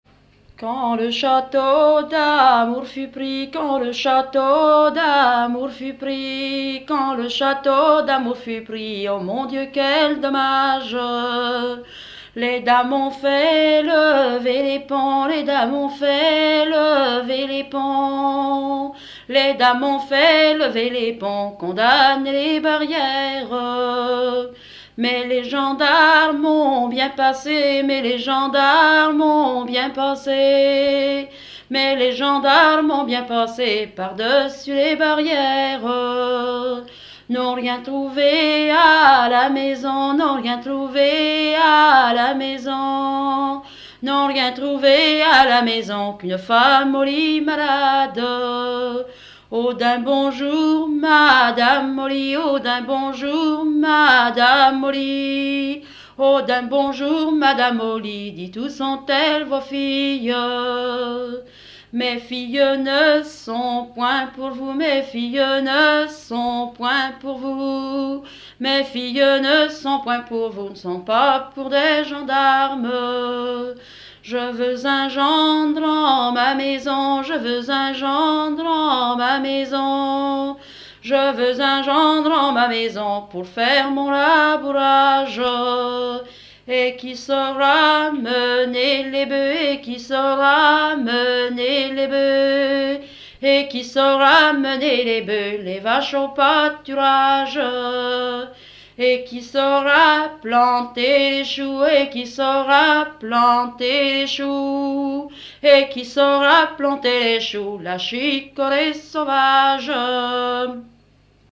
danse : ronde
Genre laisse
Pièce musicale inédite